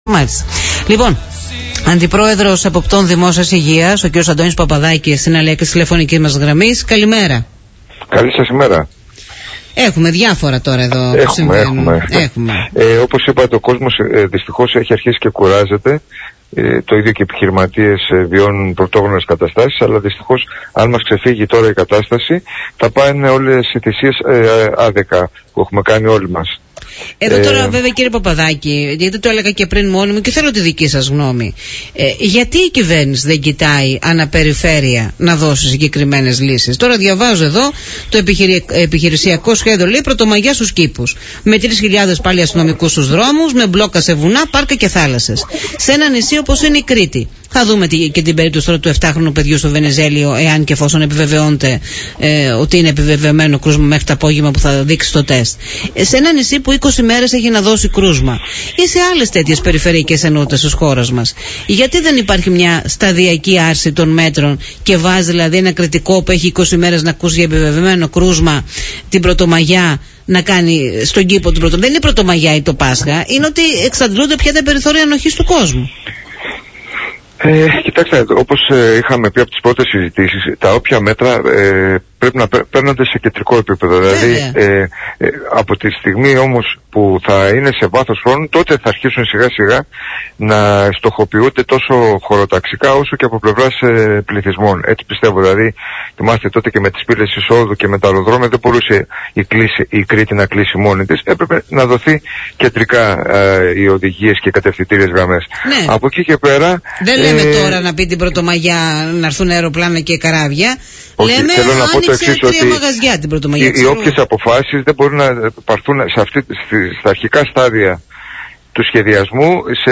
Εντείνονται οι ψεκασμοί για τα κουνούπια από την Περιφέρεια Κρήτης, όπως επεσήμανε μιλώντας στον Politica 89.8